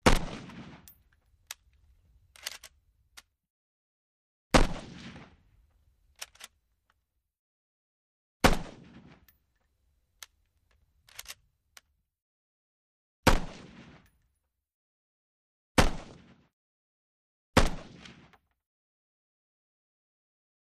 M-14 Nato: Single Shots And Cocks. Shell Casings Fall On Hard Surface. Dual